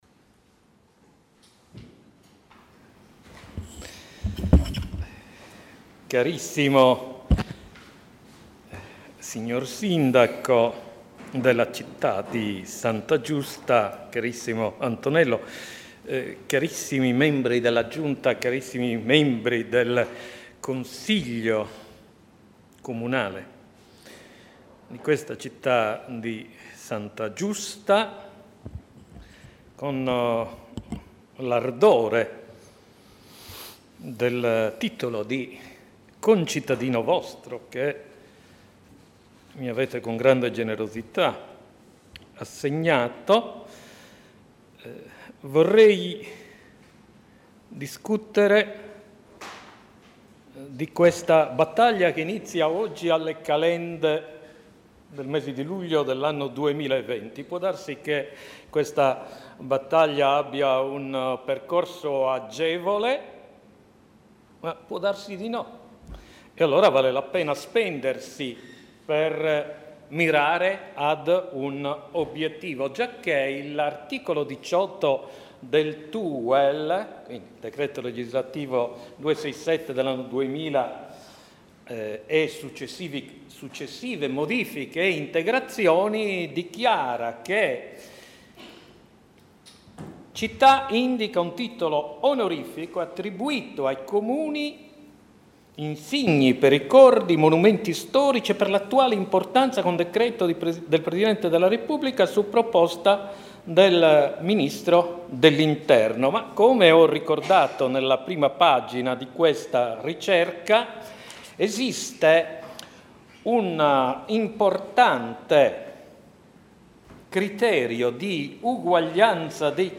Consiglio Comunale straordinario per la celebrazione del 70° anniversario dalla ricostituzione del Comune di Santa Giusta -
Audio della seduta - prima parte